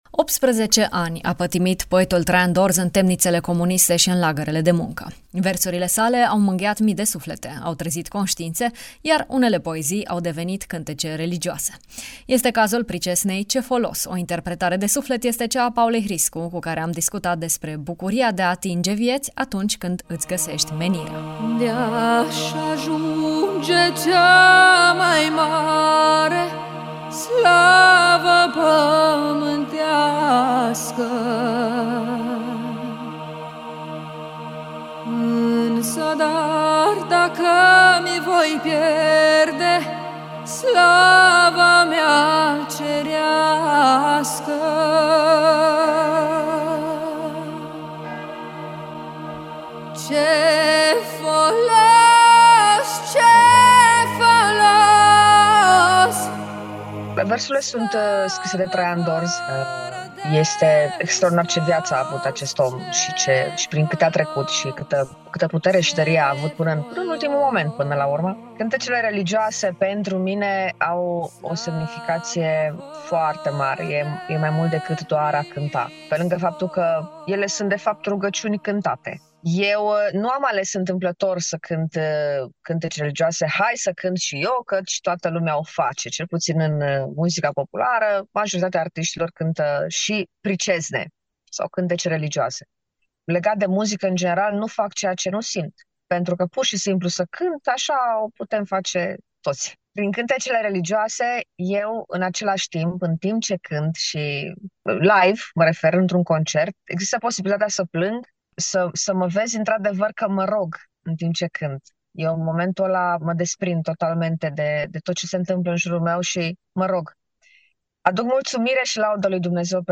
20-apr-BDF-Ce-folos-cu-instr.mp3